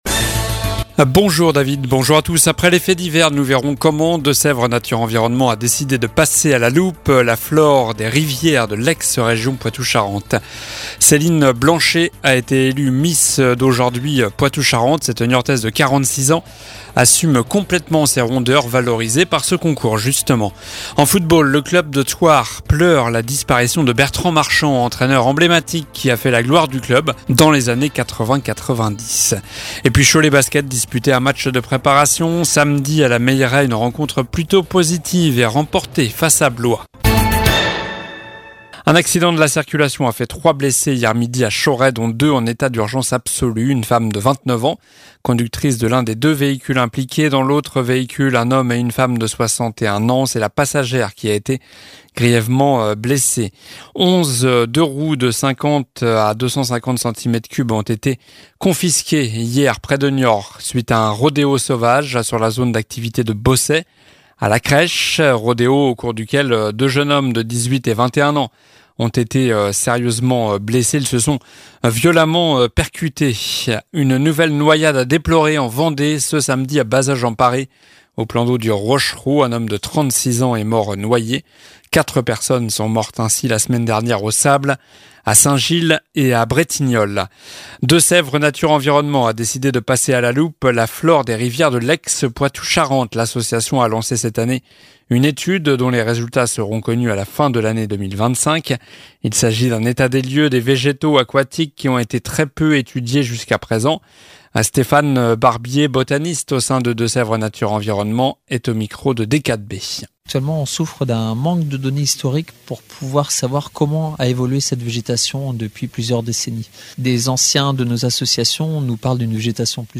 Journal du lundi 28 août (midi)